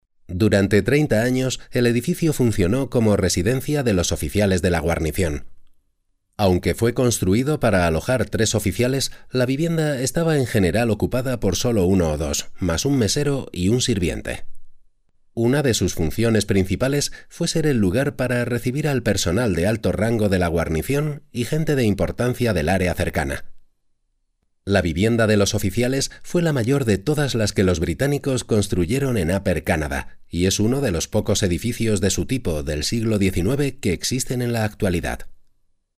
Home studio: Mic AKG C3000 Pre Studio Projects VTB1 Card Tascam US-122MK2 SE Reflection Filter Reaper software edition Vox Studio
Locutor español nativo, voz cálida, e-learning, spots, documental, audiolibros, corporativos
Sprechprobe: Sonstiges (Muttersprache):
Persuasive voice for advertising. Serious tone and corporate business. And communicative teaching style for e-learning, off and phone voice. Narrative voice warm, friendly and artistic for audiobooks.